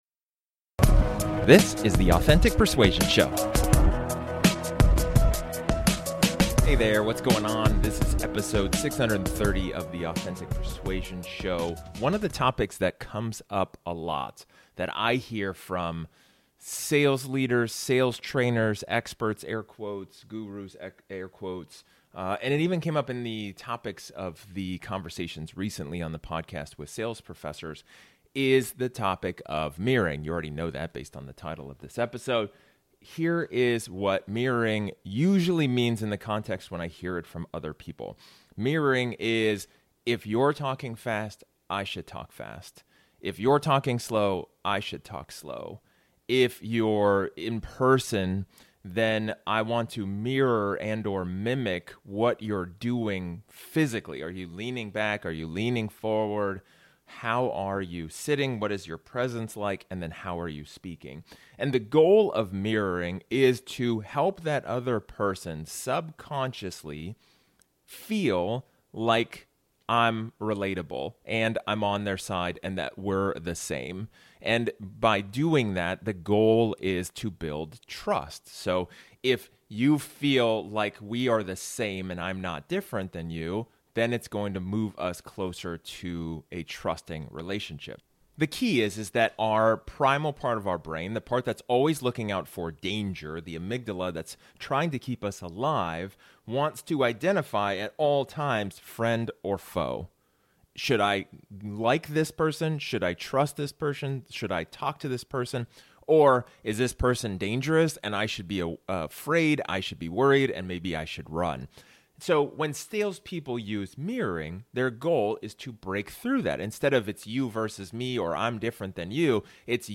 In this solo episode, we delve into the fascinating world of mirroring in sales conversations.